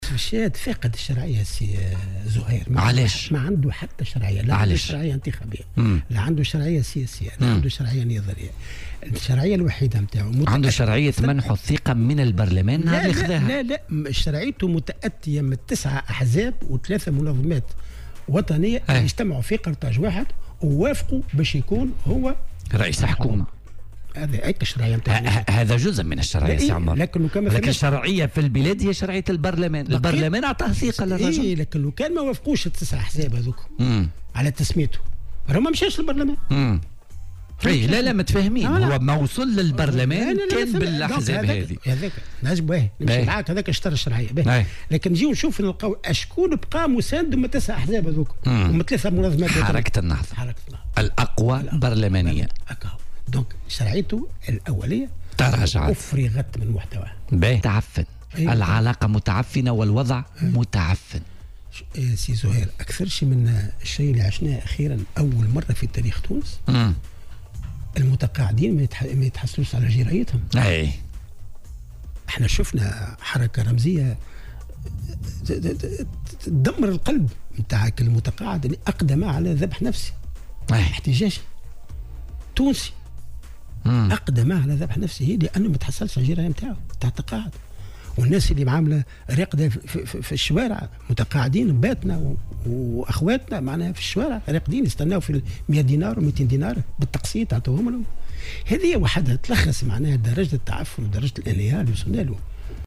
وأوضح في مداخلة له اليوم في برنامج "بوليتيكا" أن جميع الأحزاب والمنظمات الوطنية التي اتفقت على تسميته رئيسا للحكومة في اتفاق قرطاج 1 سحبت منه البساط ولم يتبق من مسانديه إلا حركة النهضة.